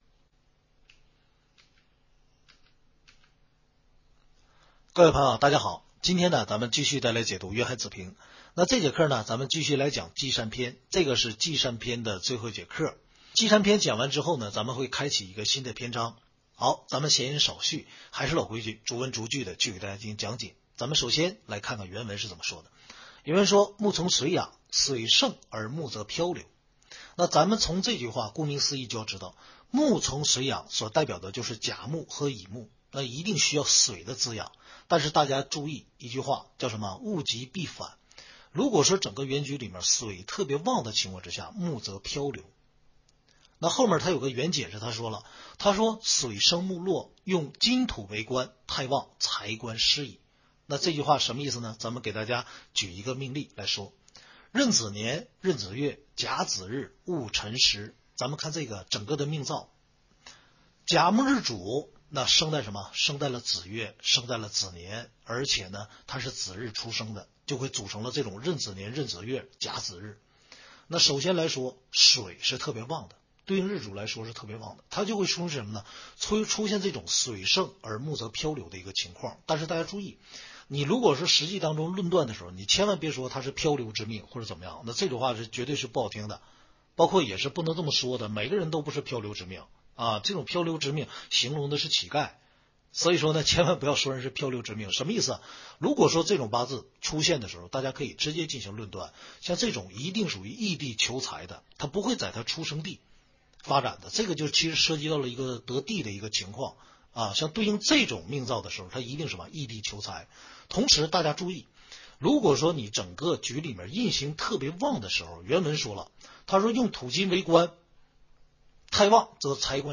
听书渊海子平白话文